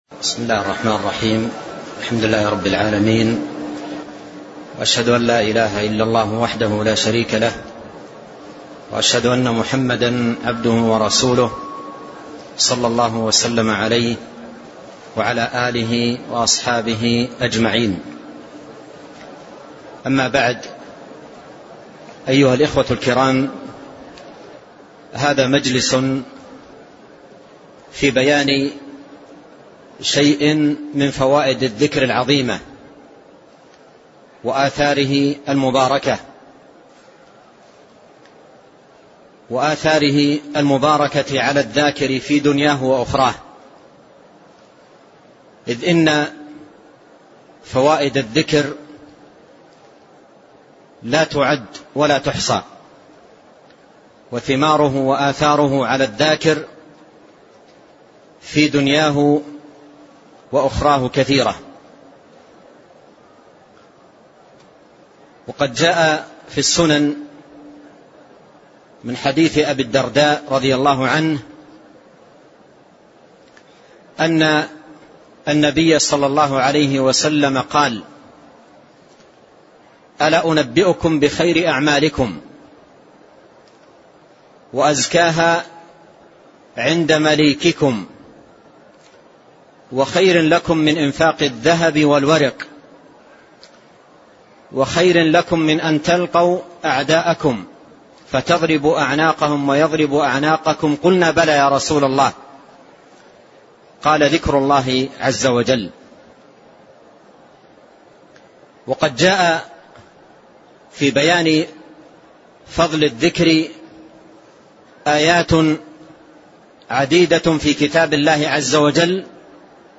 محاضرة صوتية نافعة، وفيها بيان فوائد الذكر وثمراته، مع